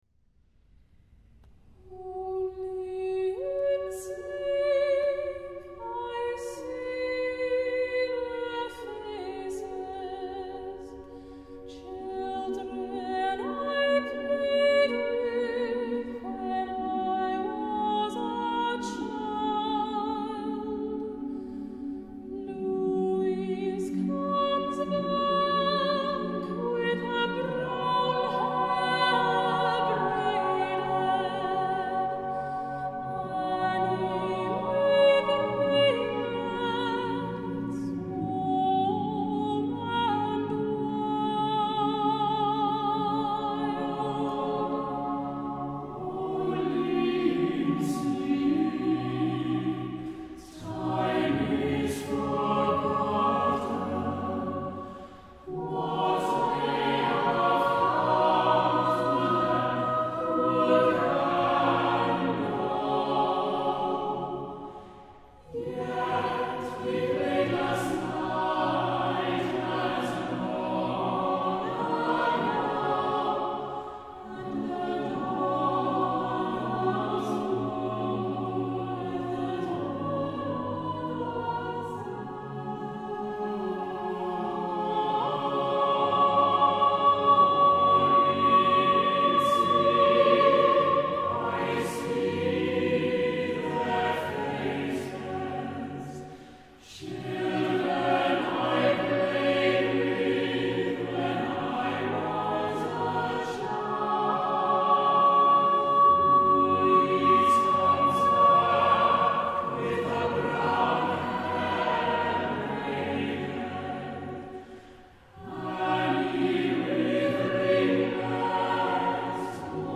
Choral music